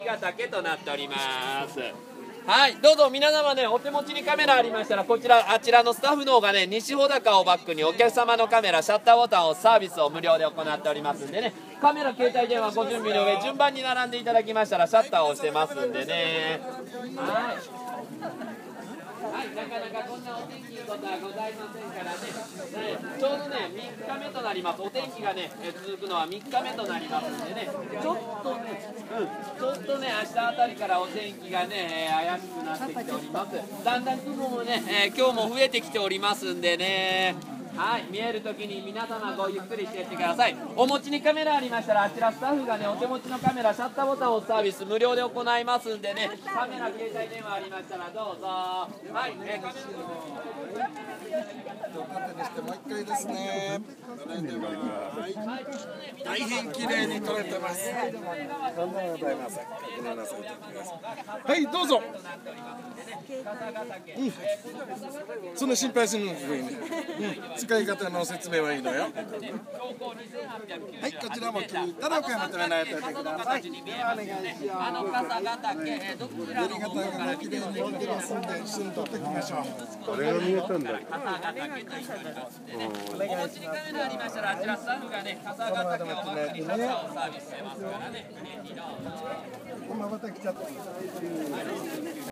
Japan Mountain Top Sound
One thing hard to find in Japan (and that may be hard to believe) is a quiet place. Listen to the sound of a viewing platform at the top of a cable car at 2100m.
japan-mountain-top.m4a